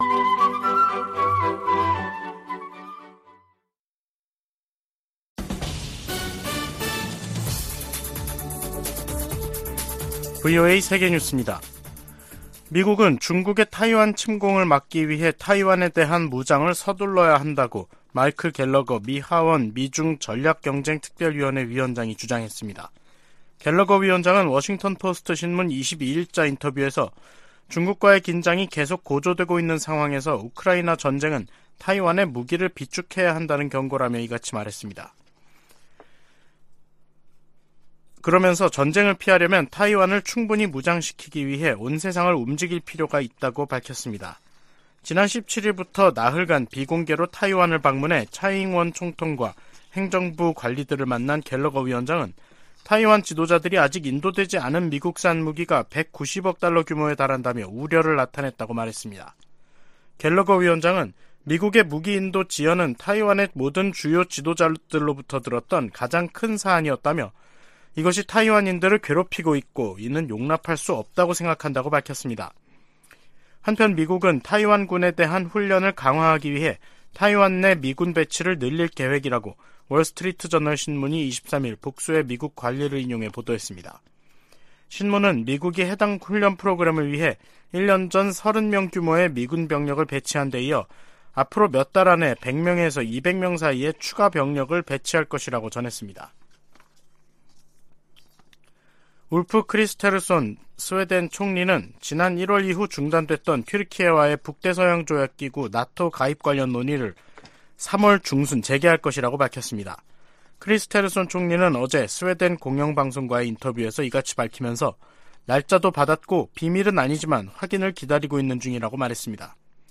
VOA 한국어 간판 뉴스 프로그램 '뉴스 투데이', 2023년 2월 23일 3부 방송입니다. 미 국무부가 북한의 최근 ICBM 발사를 거듭 규탄한 가운데 국방부는 북한의 계속되는 탄도미사일 시험 발사가 역내 불안정을 야기한다고 비판했습니다.